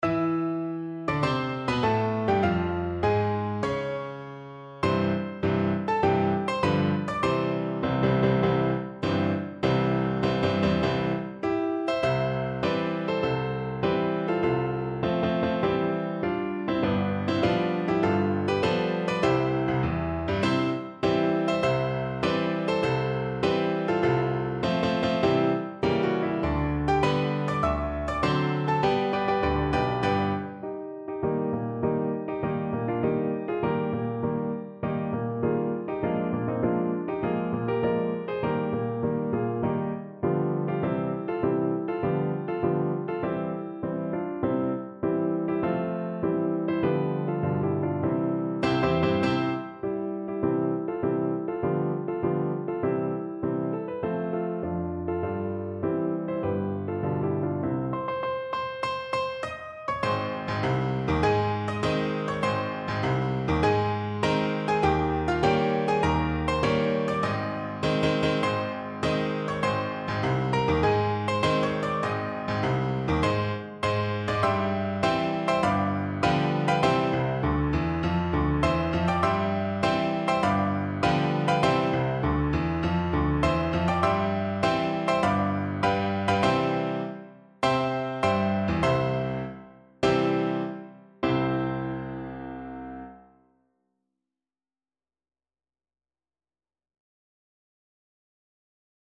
4/4 (View more 4/4 Music)
Marcial
Traditional (View more Traditional Voice Music)